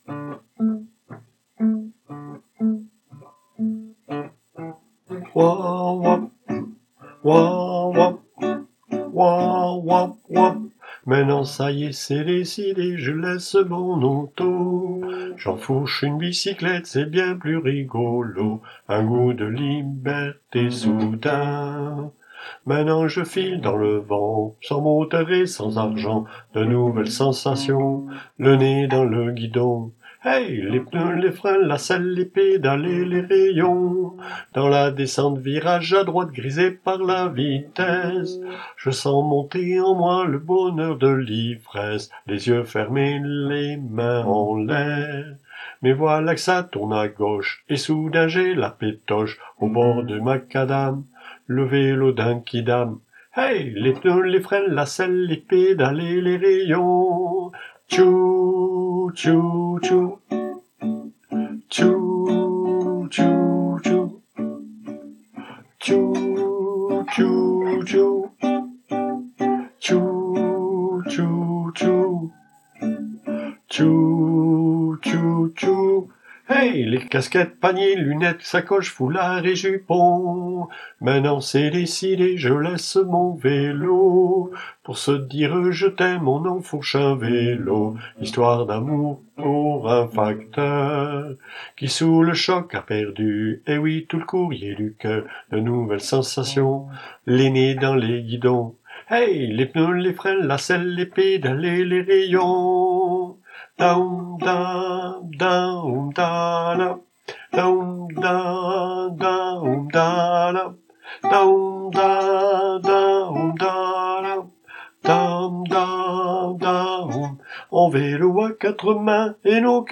ALTI